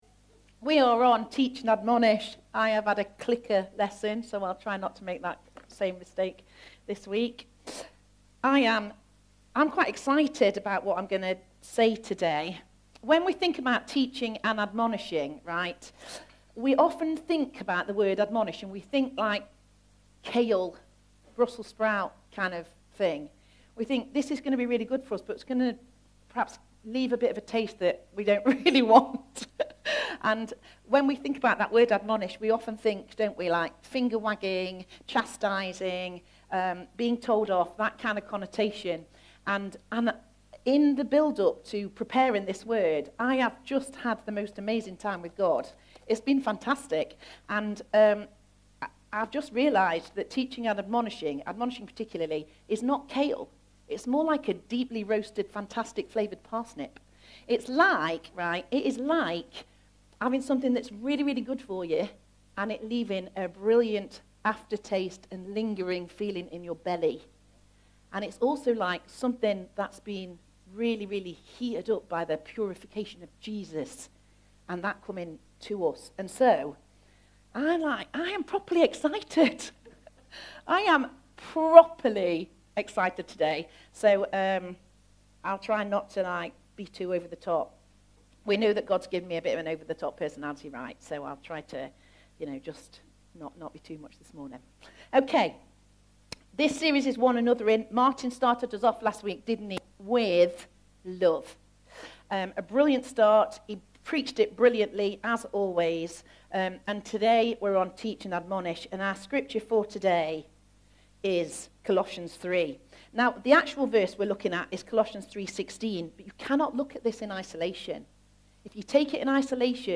A message from the series "Sunday Morning." In the second message of our 'One Another' series we look at what it means to teach and admonish one another.